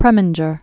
(prĕmĭn-jər), Otto Ludwig 1905-1986.